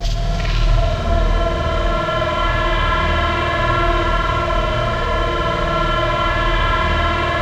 ATMOPAD04.wav